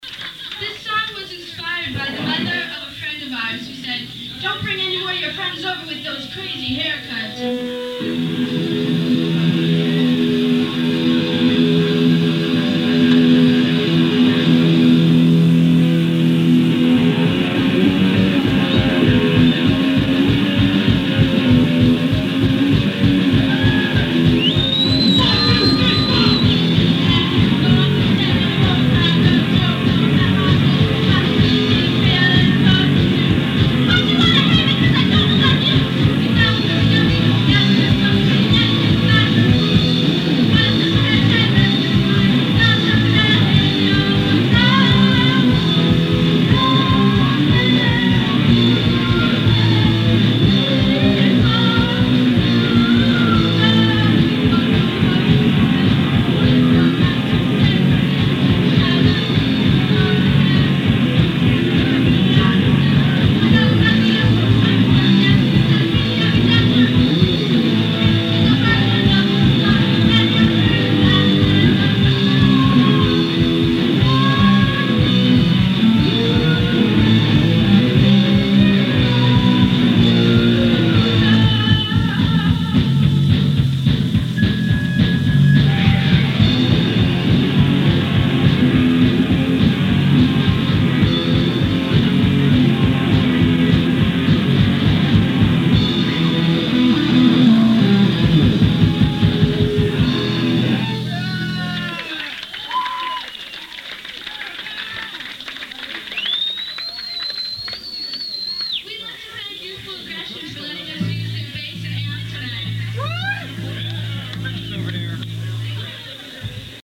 @ Caddyshack, Catasaque PA 1-19-86